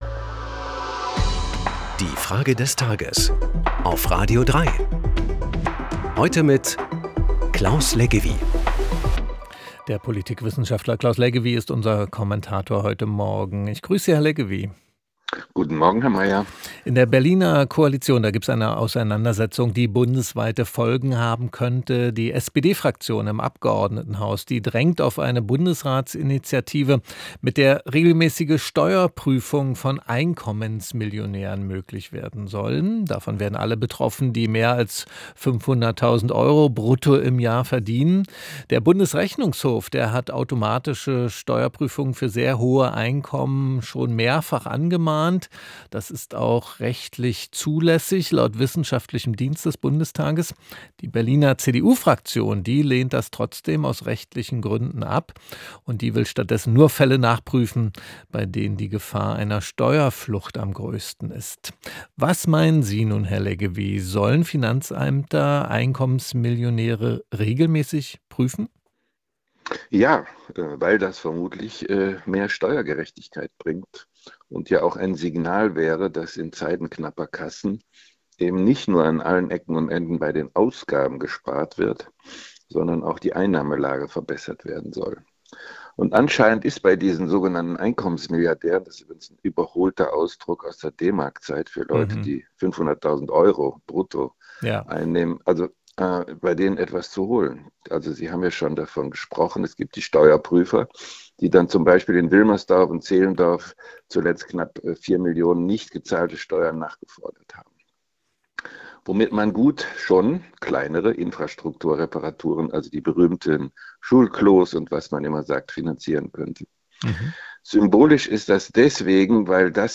Unsere Frage des Tages an den Politikwissenschaftler Claus Leggewie